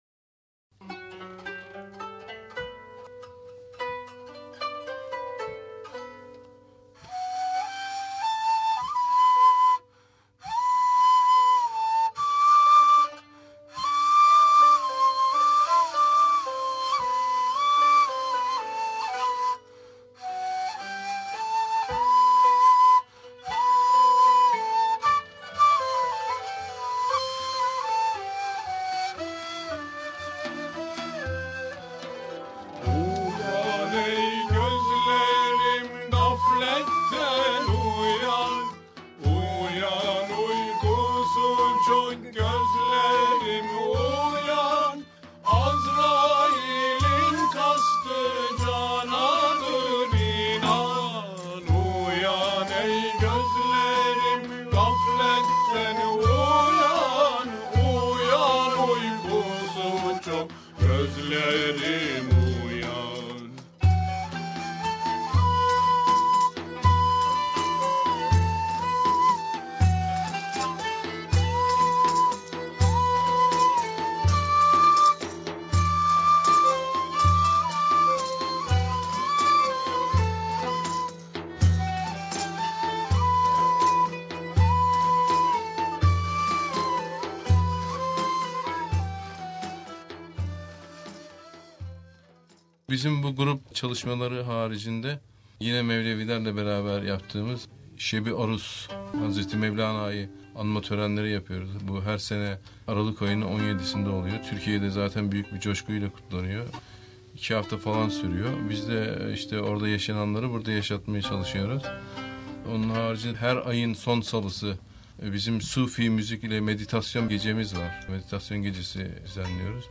SBS Türkçe programı, Avustralya Sufi Müzik Grubu'nun üyeleri ile SBS stüdyolarında söyleşi gerçekleştirirken Grup da Sufi müziğinden örnekler sundu. Söyleşinin ikinci bölümünü dinleyebilirsiniz.